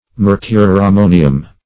Search Result for " mercurammonium" : The Collaborative International Dictionary of English v.0.48: Mercurammonium \Mer`cur*am*mo"ni*um\, n. [Mercuric + ammonium.]
mercurammonium.mp3